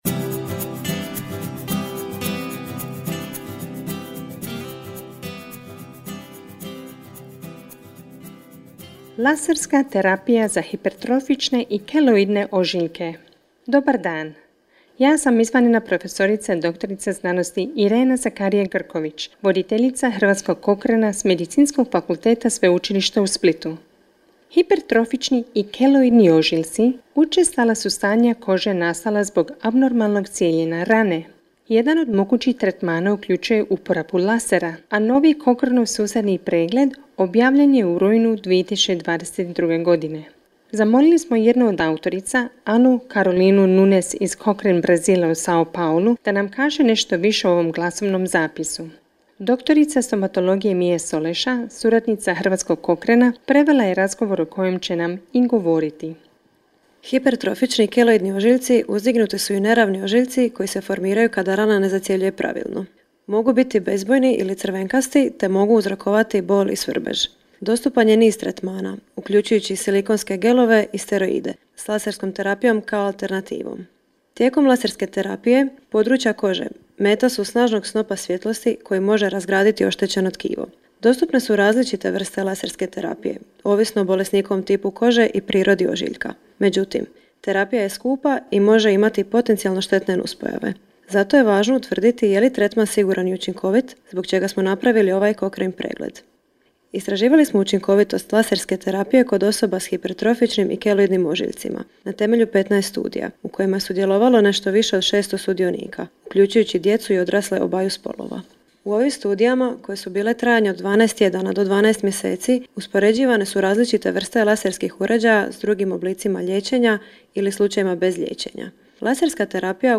Cochrane proizvodi sustavne preglede koji su prepoznati kao najviši standard izvora informacija o zdravstvenog skrbi utemeljenoj na dokazima. Slušajte autore Cochraneovih sustavnih pregleda kako jednostavnim jezikom opisuju rezultate i dokaze iz svojih utjecajnih radova.